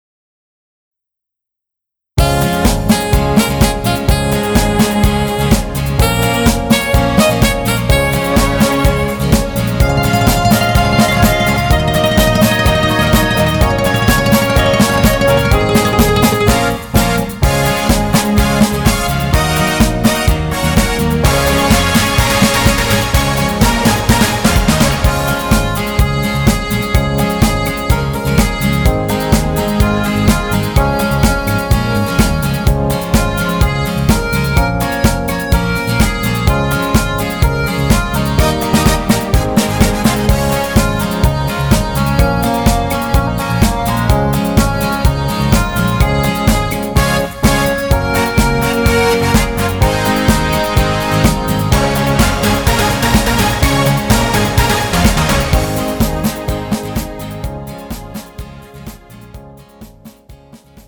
음정 남자-1키
장르 가요 구분 Pro MR